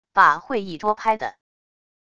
把会议桌拍的wav下载